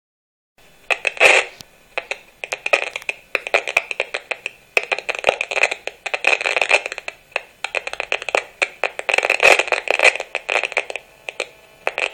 Si bien nuestros oidos no puden escuchar las radiaciones (Microondas) de los artefactos inalámbricos, un analizador de frecuencia es capaz de convertirlas en audio.